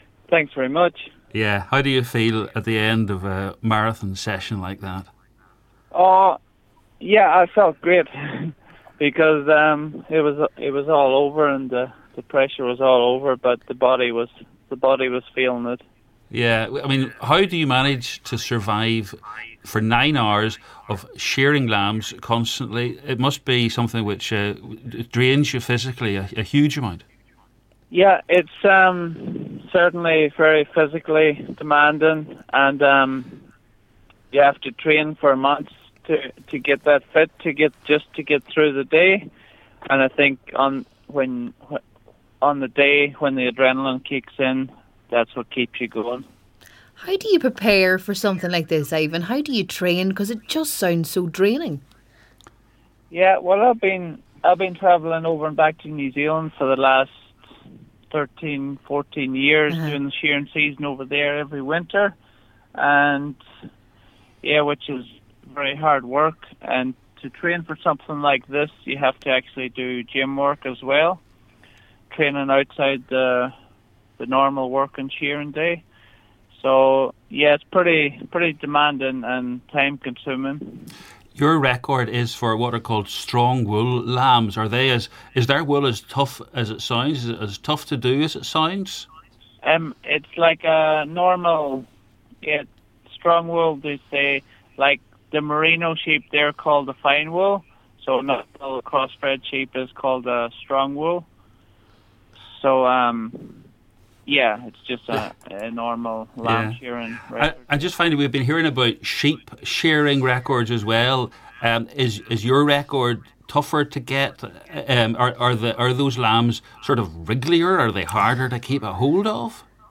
Listen: Donegal man tells of world sheep-shearing record set in nine hours